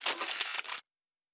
pgs/Assets/Audio/Sci-Fi Sounds/Weapons/Weapon 08 Reload 2 (Laser).wav at master
Weapon 08 Reload 2 (Laser).wav